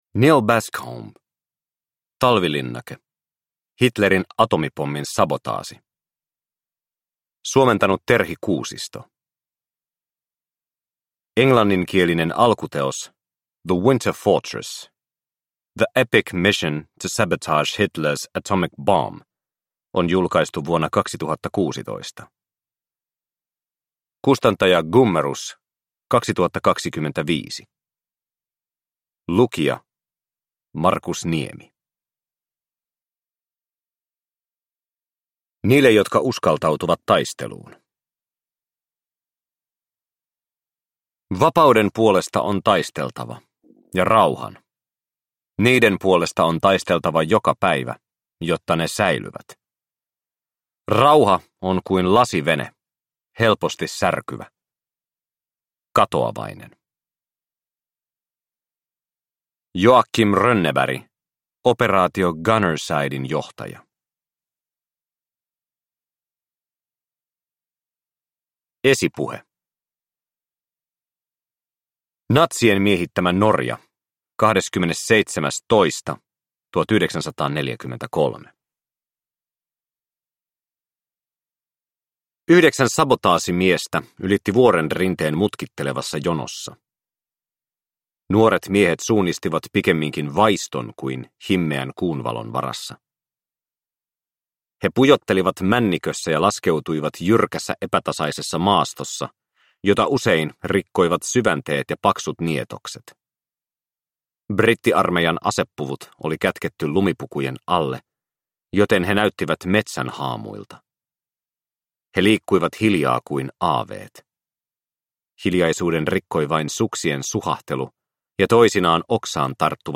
Talvilinnake – Ljudbok